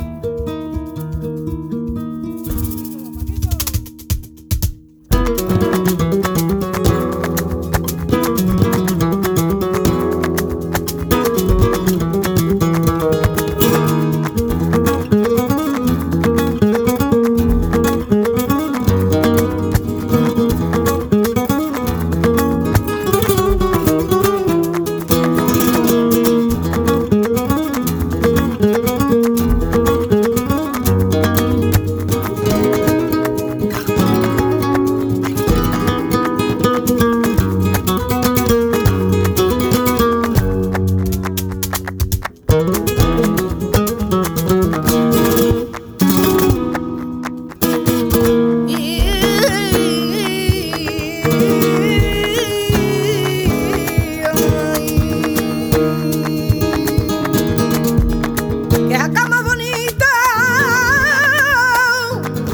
Zurück zu: Flamenco
Bulerías 4:04